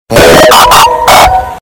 Android Notification Bass Boosted
bGrIkUxL-Android-Notification-Bass-Boosted.mp3